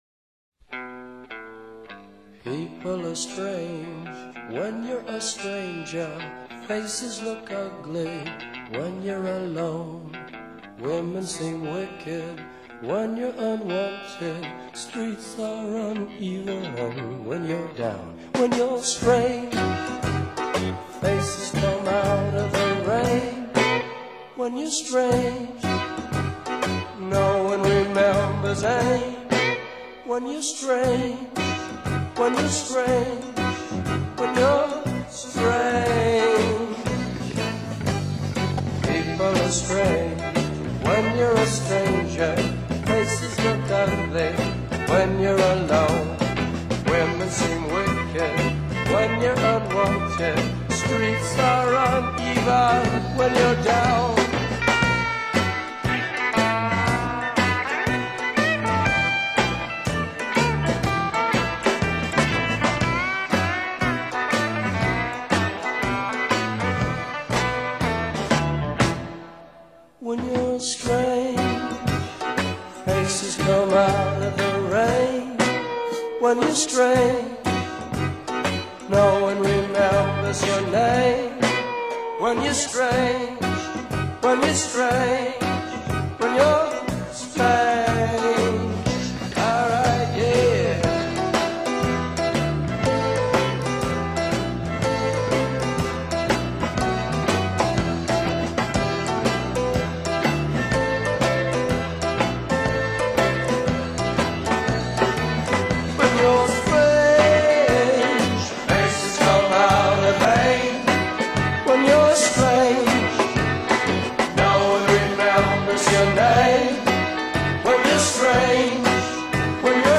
Guitar
Keyboards, Vocals
Drums